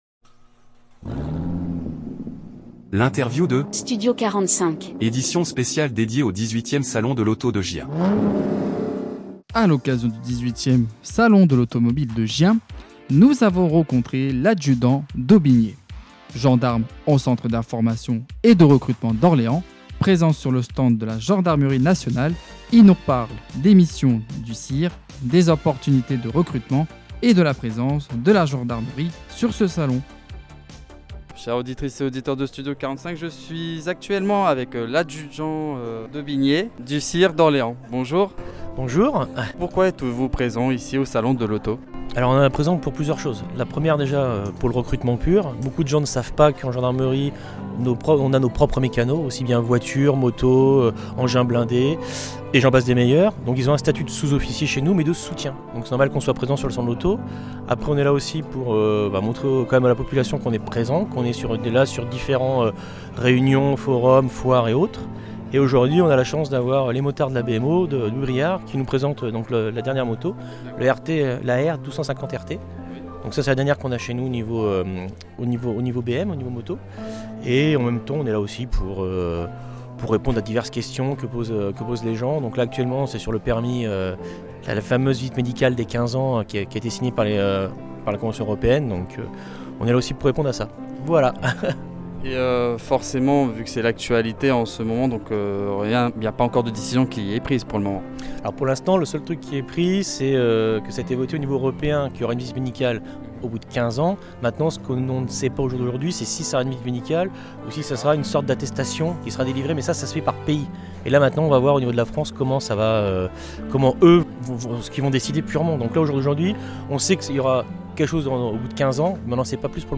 Interview Studio 45 - édition spéciale Salon de l'Auto Gien 2025 - CIR d'Orléans
Dans cet épisode, focus sur le Centre d’Information et de Recrutement (CIR) de la Gendarmerie d’Orléans, présent lors du Salon de l’Auto de Gien.Les représentants du CIR nous parlent de leur mission principale : informer et recruter celles et ceux qui souhaitent s’engager dans la Gendarmerie nationale.